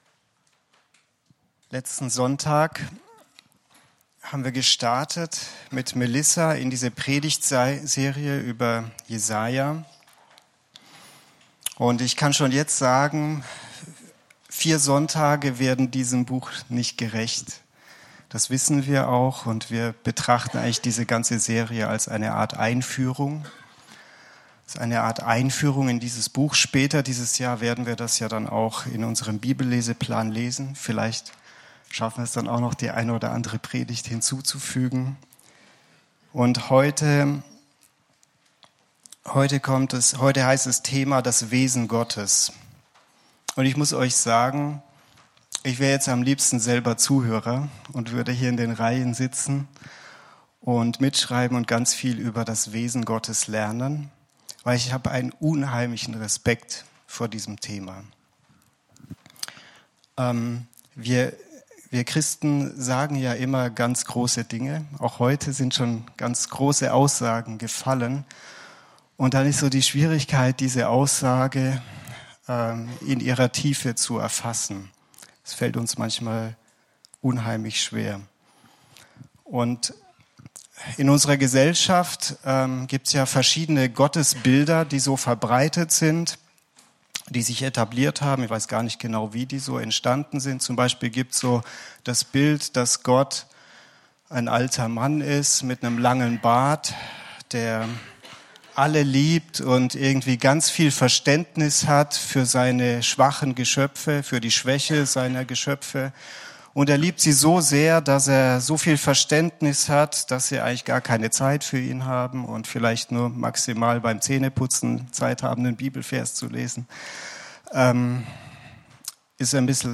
Predigtserie „JESAJA“ – bmg Leonberg